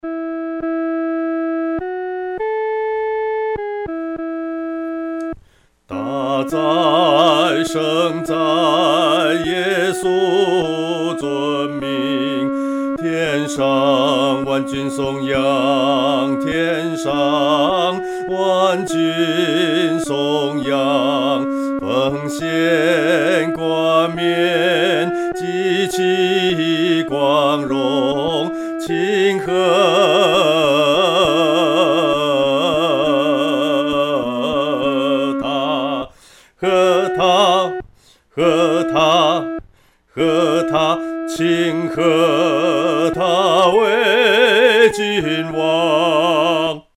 独唱（第二声）